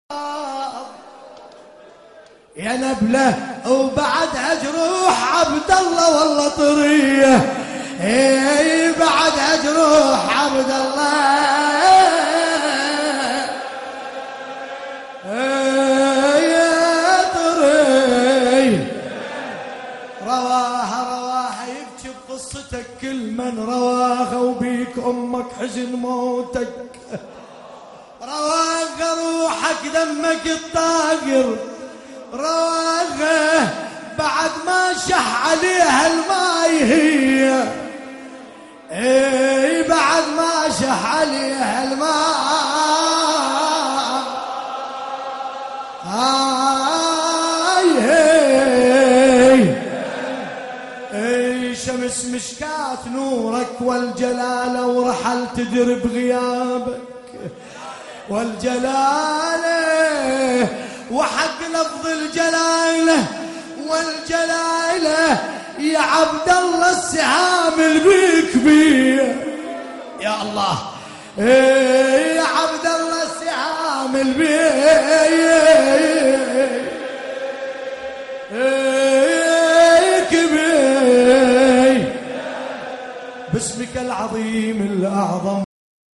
ياعطشان - نعي